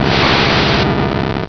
sound / direct_sound_samples / cries / alakazam.wav